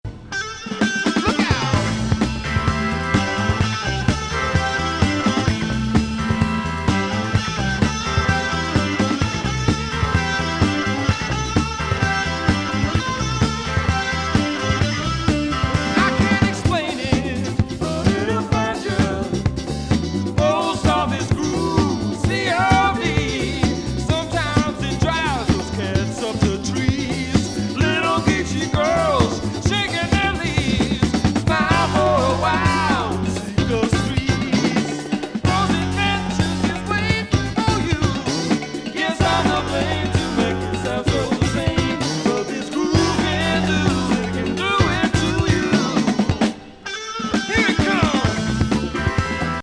ダンサブル・ファンキー・ロック